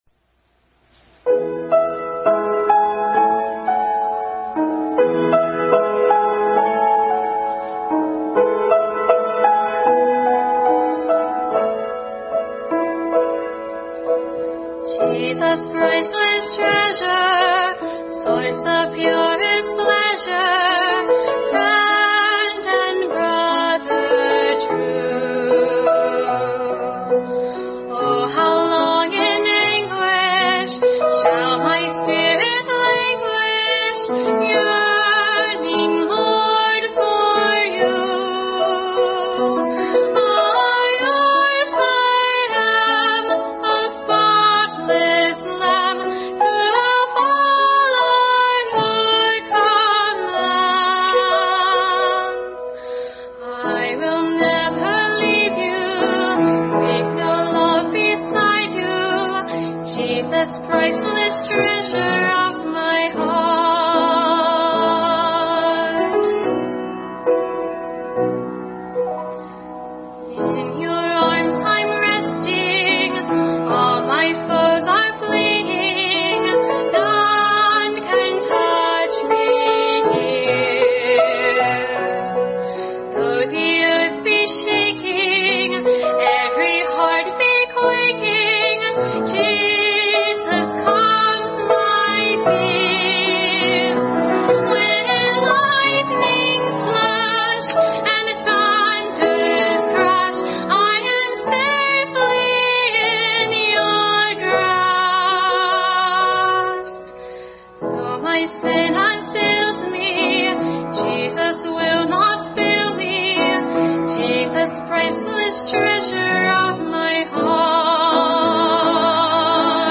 Bible Text: 1 Corinthians 15:50-58, 1 Thessalonians 4:13-18 | Family Bible Hour message. Preceded by special music; Jesus Priceless Treasure of My Heart